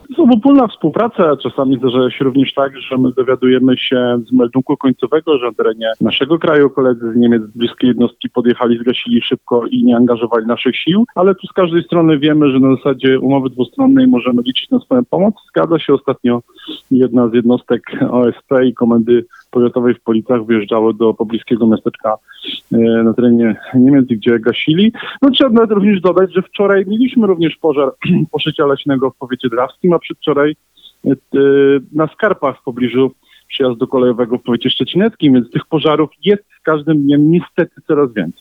ROZMOWA DNIA